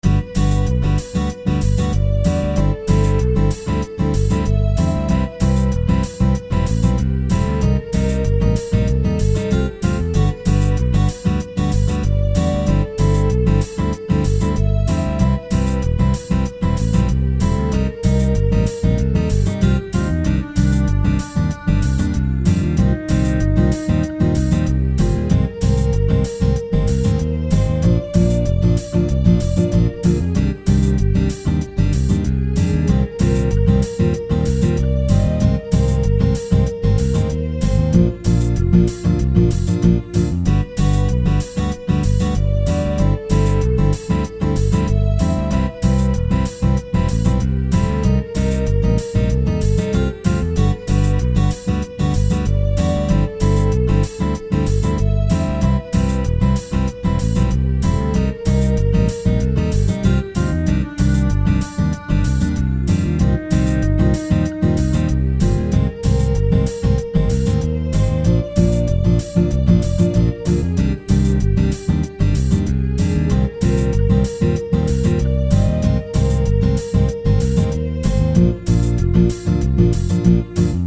テレビのワイドショー・ニュース番組のBGMで流れてそうな、シンプルなループ曲。